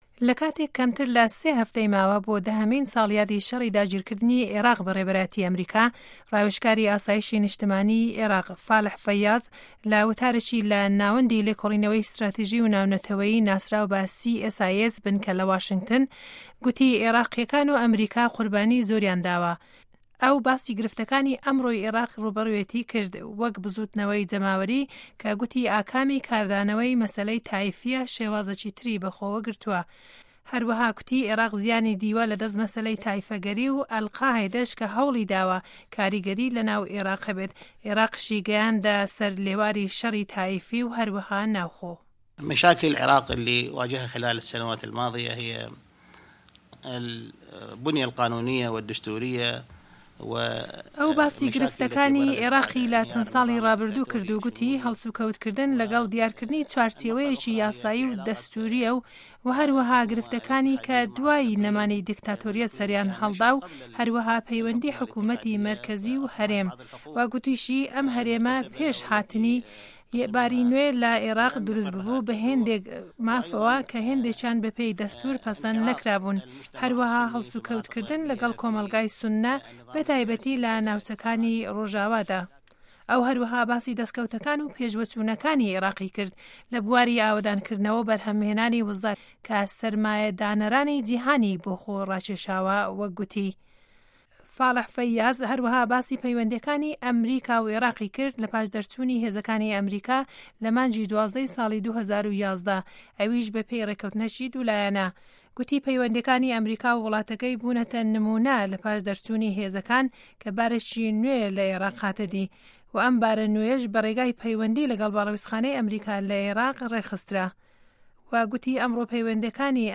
گوتاری ڕاوێژکاری ئاسایشی نیشتیمانی عێراق